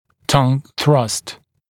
[tʌŋ θrʌst][тан сраст]прокладывание языка, эндогенное давление языка